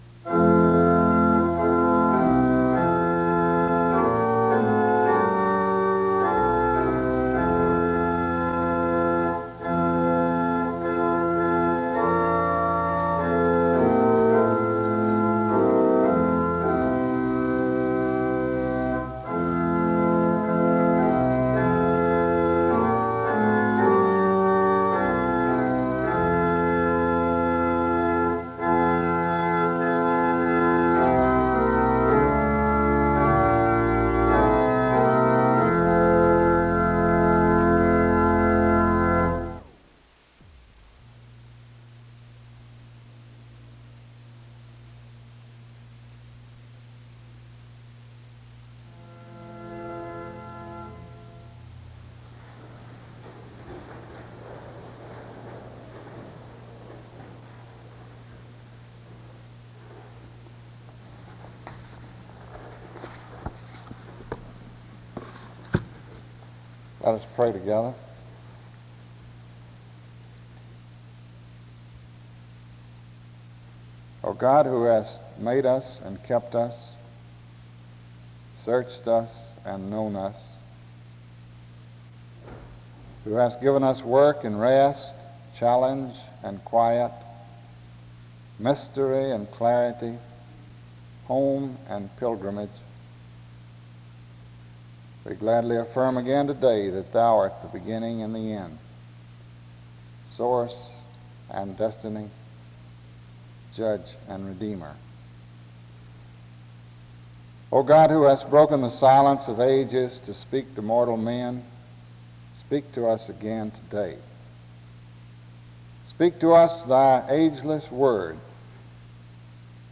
The service begins with music from 0:00-0:51. A prayer is offered from 1:07-3:15. An introduction to the speaker is given from 3:18-6:15. Special music plays from 6:20-8:21.
Closing music is sung from 1:02:42-1:02:47.
SEBTS Chapel and Special Event Recordings SEBTS Chapel and Special Event Recordings